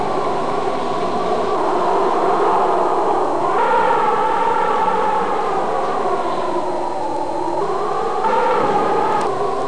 wind12.mp3